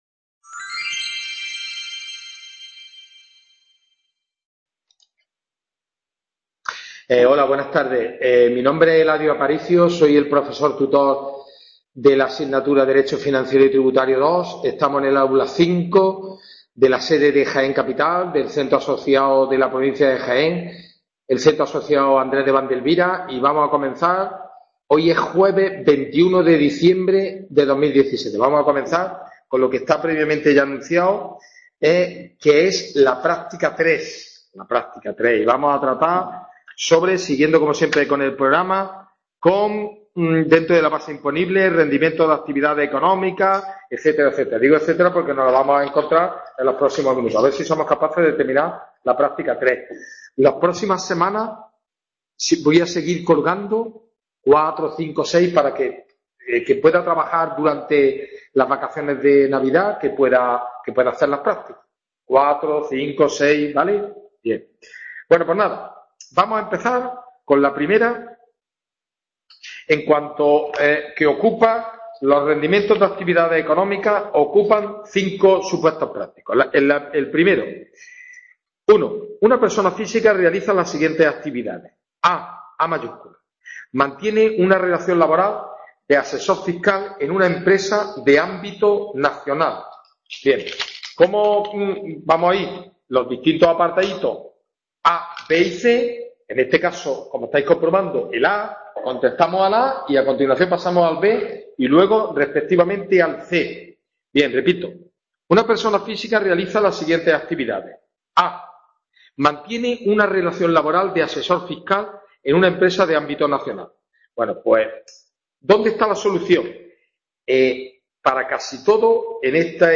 WEB CONFERENCIA